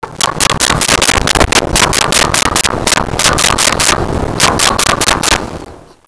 Chickens In A Barn 02
Chickens in a Barn 02.wav